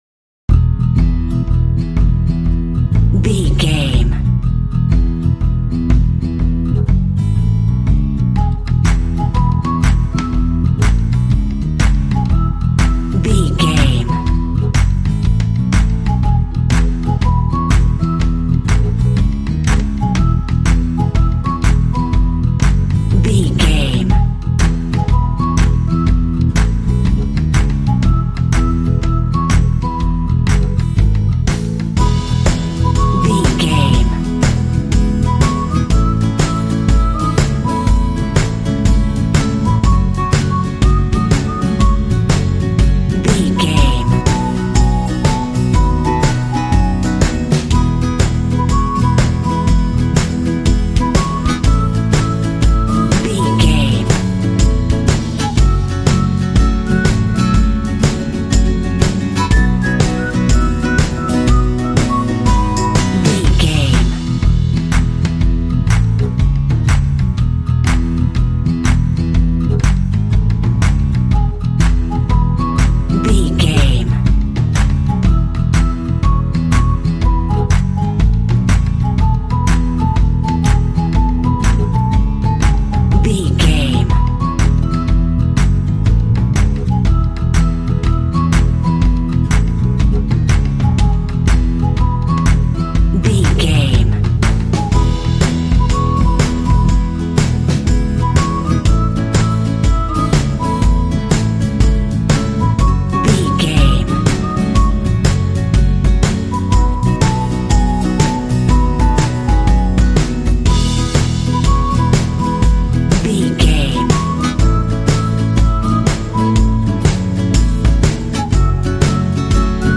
Uplifting
Ionian/Major
D
bright
happy
drum machine
strings
piano
percussion
pop
indie